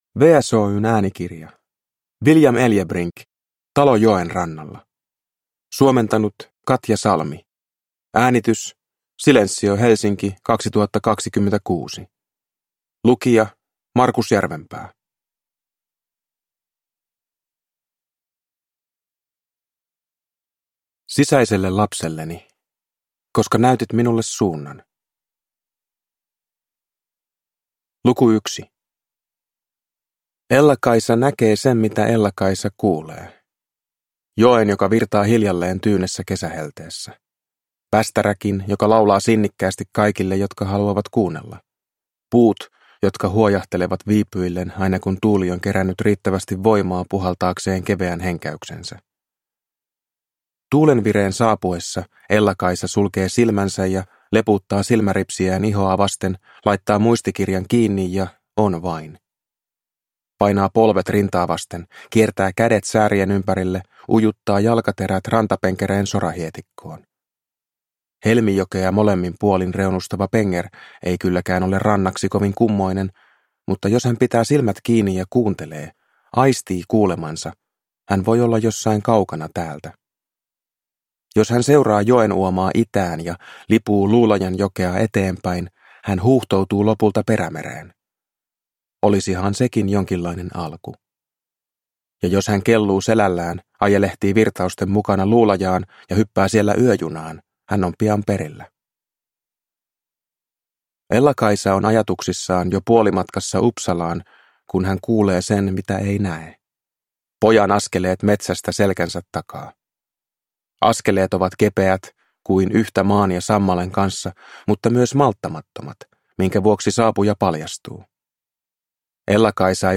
Talo joen rannalla – Ljudbok